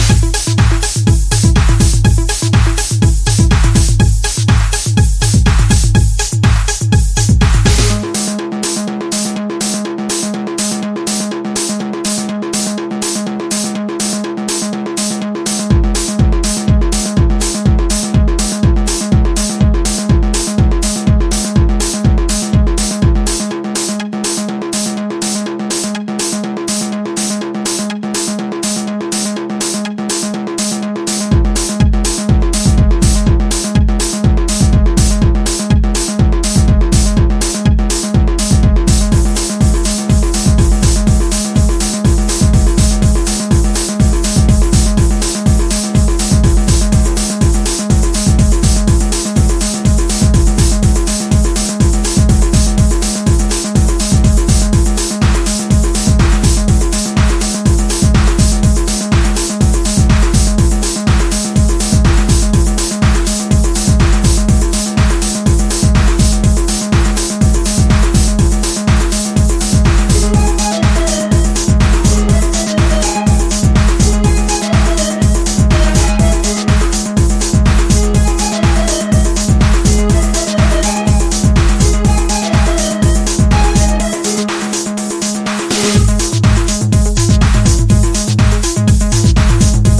初期シカゴハウスなボトムに幾重にも重なる呪術的ウワモノ。